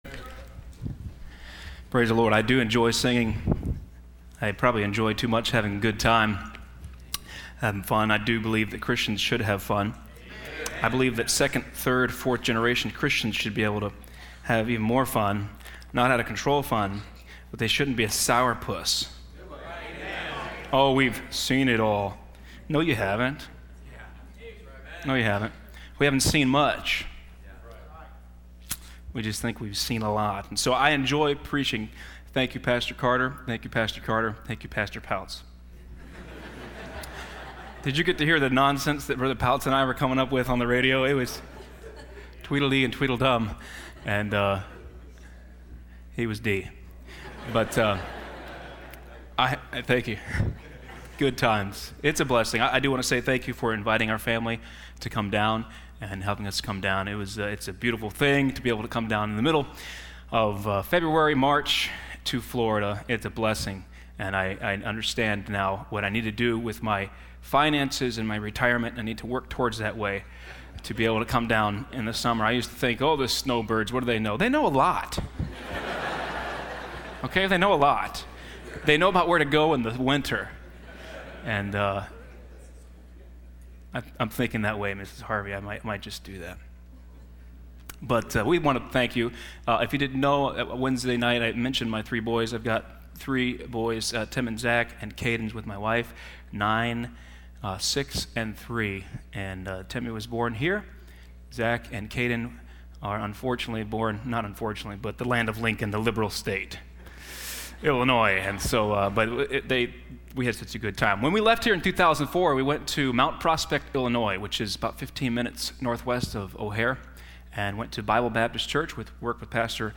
Listen to Message
Service Type: Sunday Evening